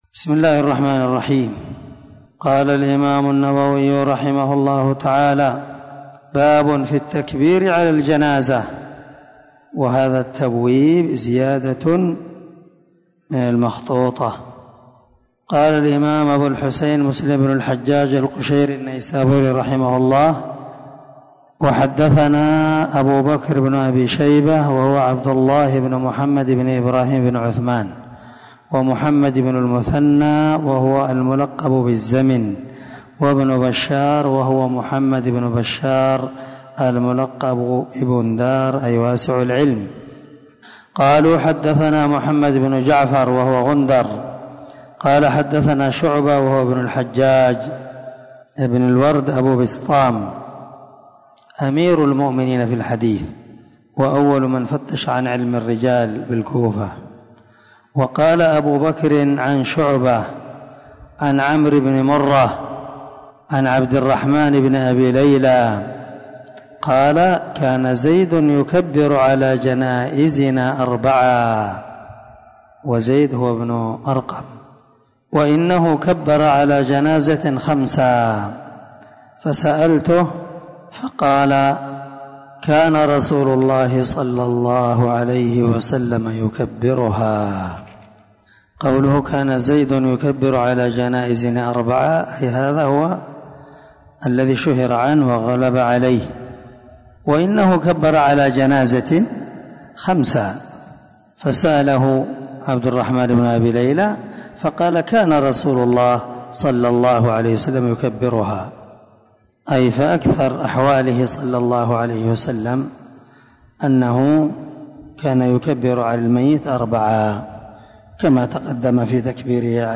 • سلسلة_الدروس_العلمية
• ✒ دار الحديث- المَحاوِلة- الصبيحة.